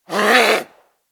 attack_7.ogg